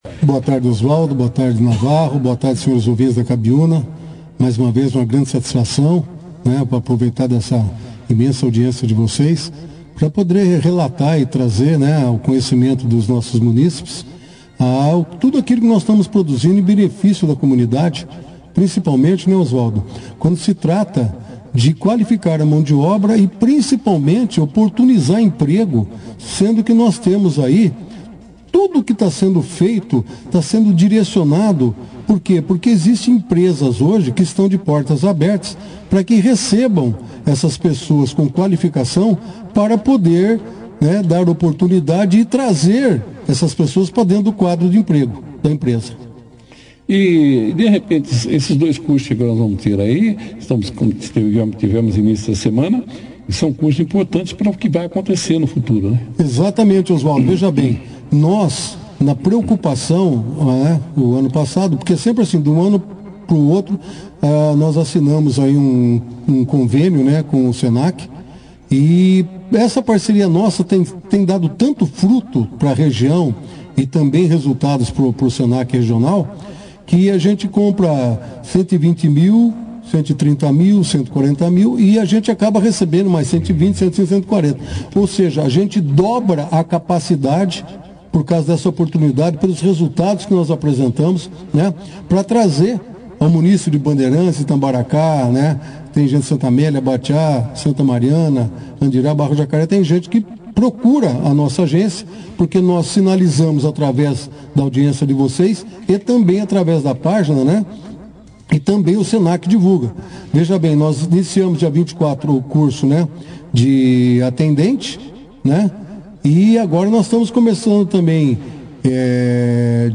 O Secretário do Trabalho e diretor-gerente da Agência do Trabalhador de Bandeirantes (SINE), Guilherme Meneghel, (foto), participou da 2ª edição do jornal Operação Cidade, desta segunda-feira, 29 de janeiro, falando detalhes sobre os cursos de formação para Recepcionistas em Meios de Hospedagem e Camareiros (as) em parceria com o SENAC.